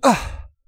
XS被击倒03.wav